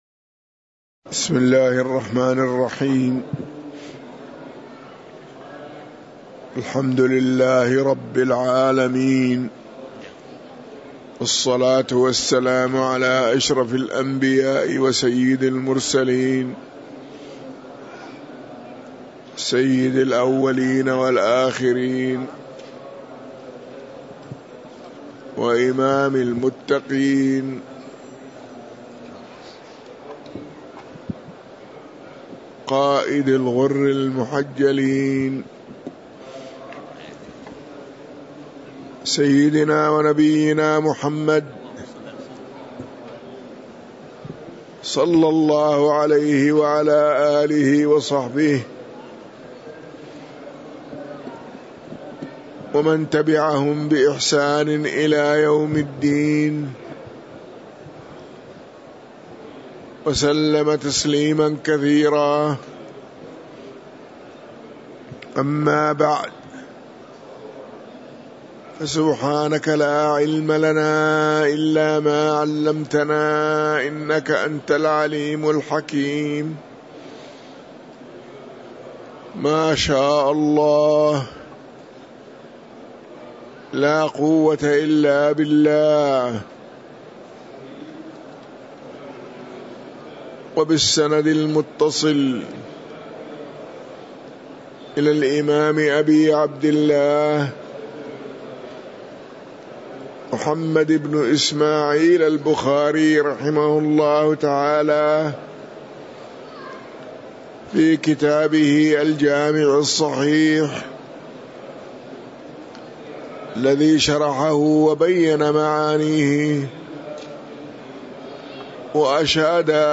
تاريخ النشر ٢٠ صفر ١٤٤٥ هـ المكان: المسجد النبوي الشيخ